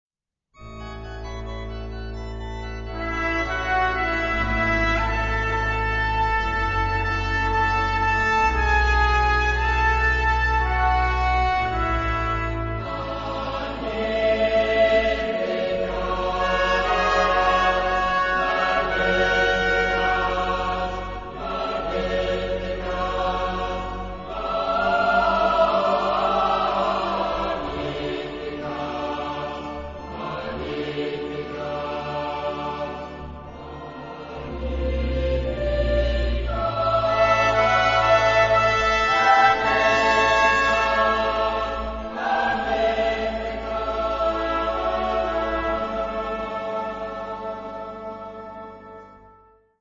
Genre-Style-Forme : Sacré ; contemporain
Type de choeur : SATB  (4 voix mixtes )
Solistes : SS  (2 soliste(s))
Instruments : Orgue (1) ; Trompette (2)
Tonalité : do majeur